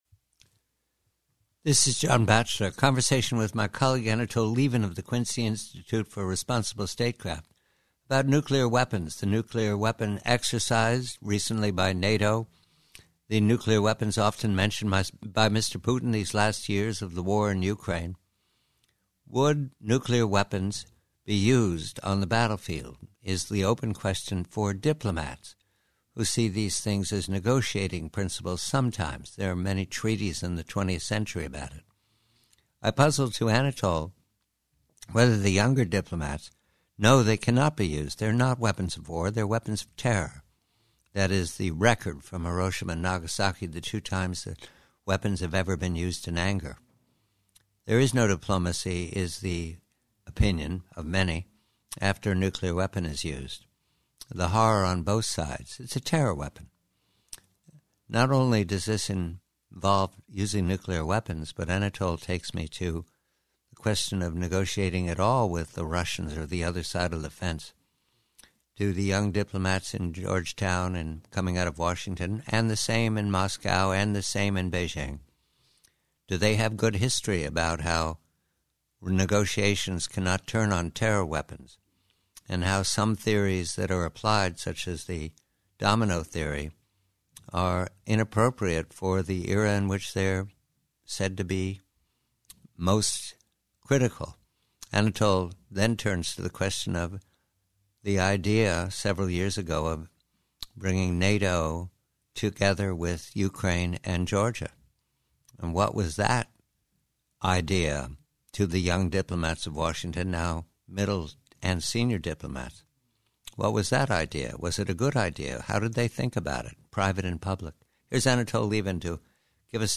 Preview: Nuclear Weapons: Conversation with colleague Anatol Lieven of Quincy Institute regarding diplomacy among the powers and nuclear weapons.